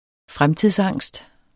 Udtale [ ˈfʁamtiðs- ]